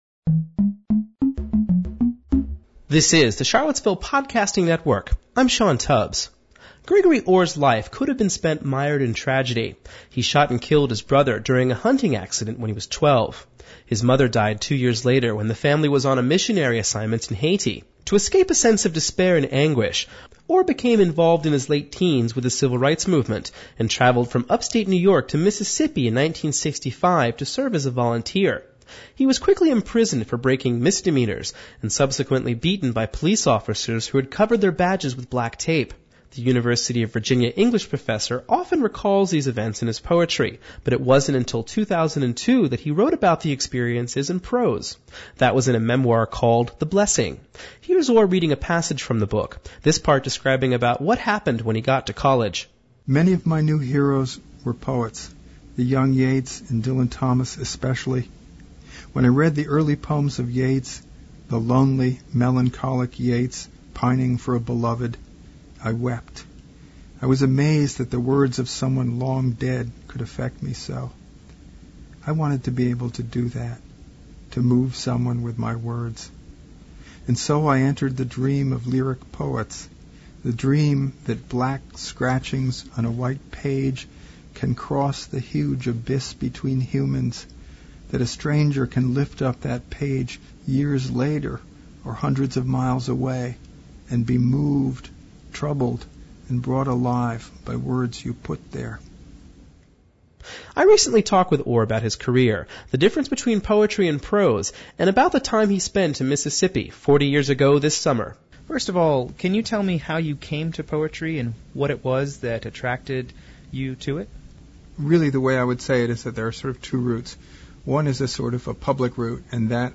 Interviews – Page 132 – Charlottesville Podcasting Network
In order to help you navigate through this file, we provide this rundown of the various people who were interviewed for this report: